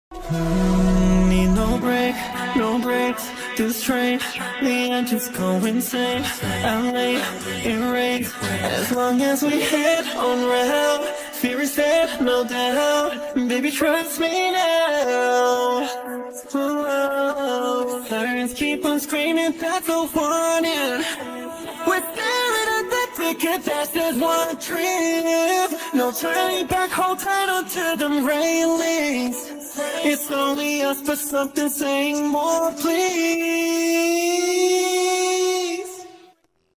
but only his voice